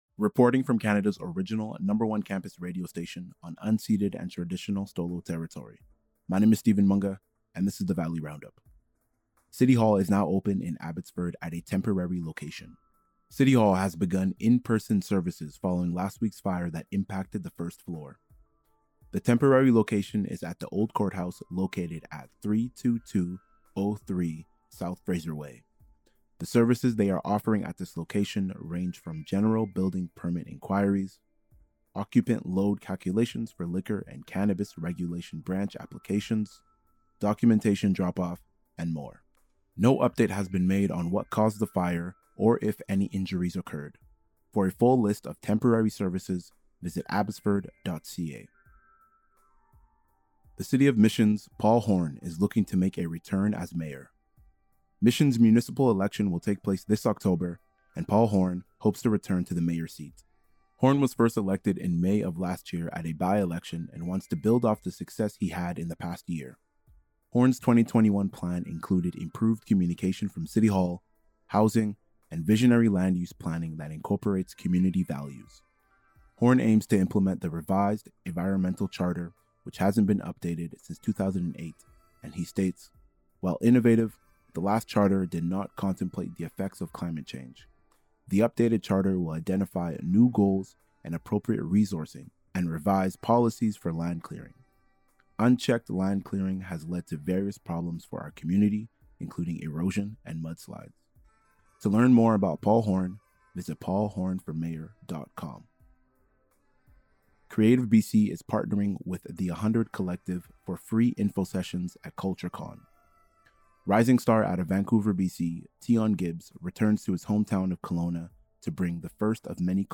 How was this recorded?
Reporting from Canada’s original #1 campus radio station, on unceded and traditional stó:lō territory.